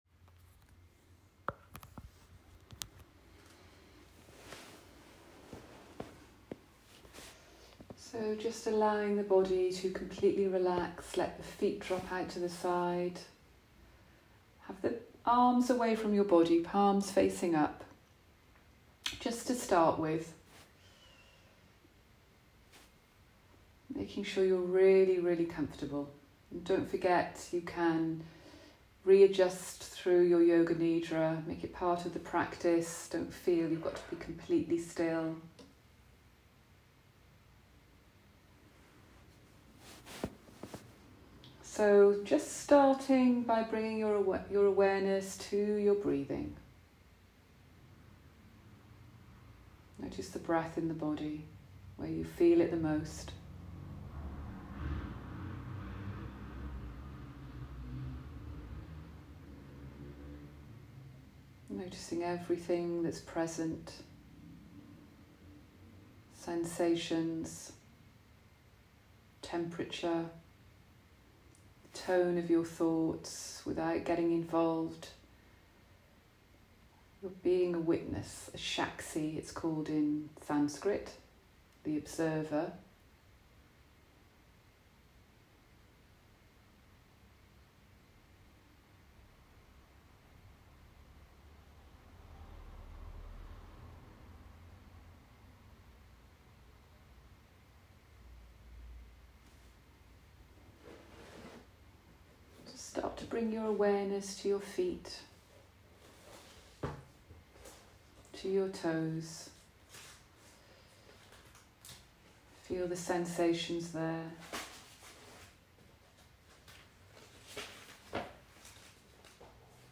Autumn Guided Meditation